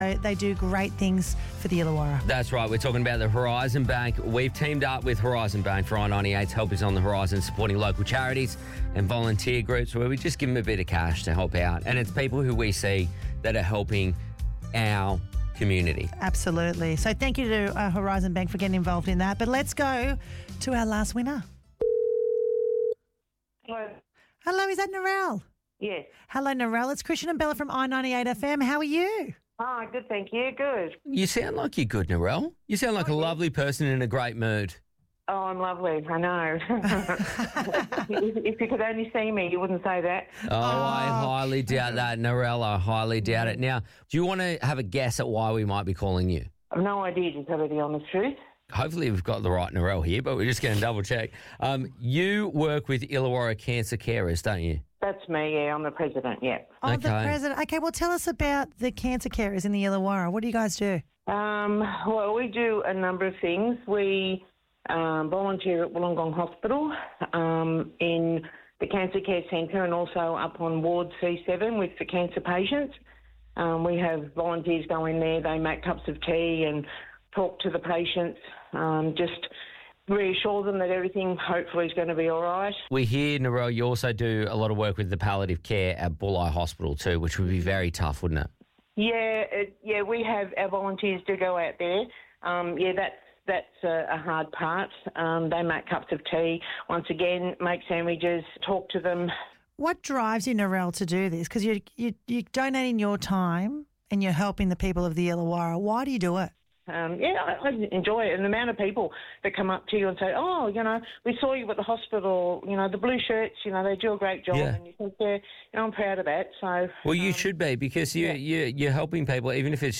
She didn’t hold a lot of hope and had actually forgotten about it, until she received a phone call last week advising she had won $500 for the Illawarra Cancer Carers – you can listen to the interview here: